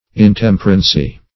Intemperancy \In*tem"per*an*cy\